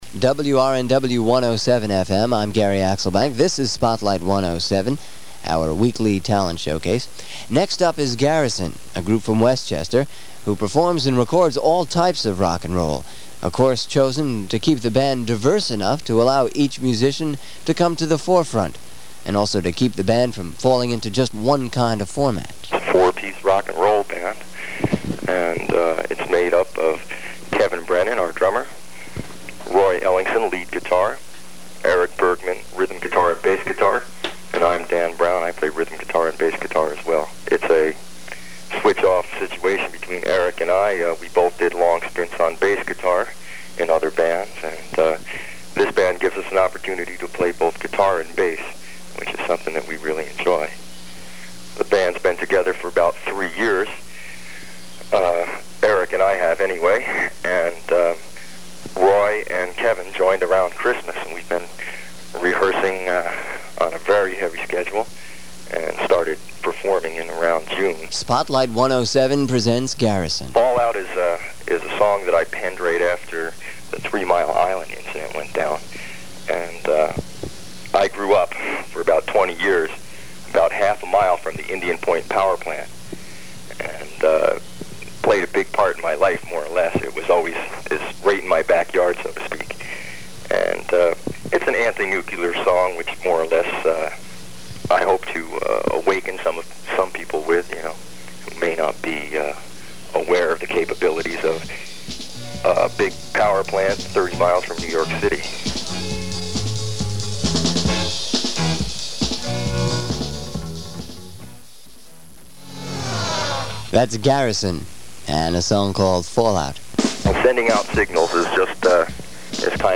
This sound bite only contains the interview portions